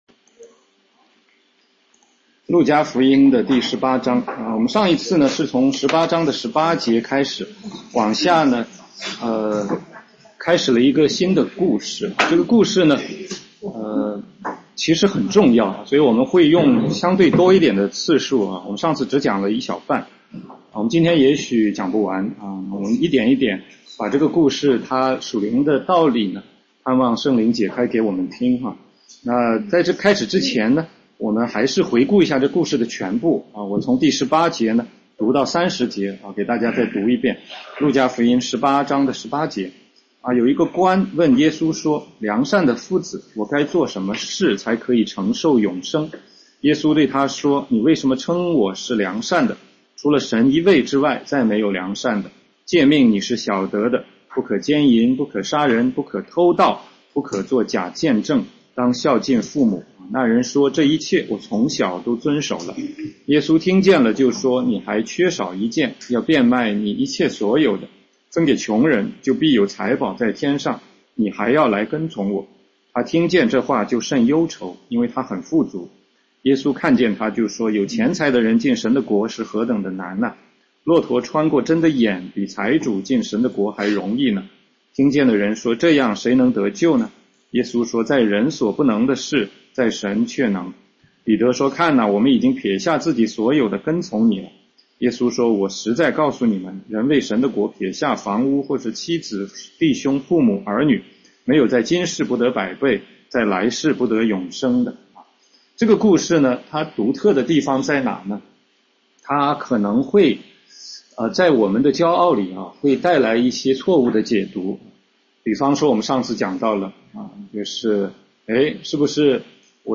16街讲道录音 - 路加福音18章24-27节：富有的官（2）